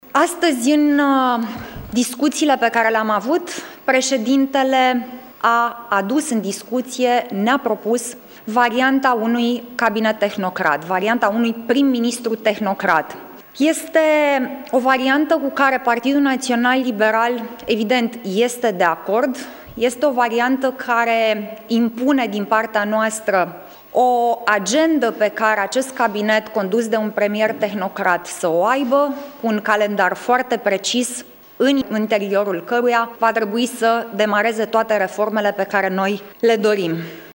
În condițiile în care la consultările de azi președintele Klaus Iohannis le-a propus soluția unui guvern tehnocrat, liberalii s-au declarat de acord, a subliniat Alina Gorghiu: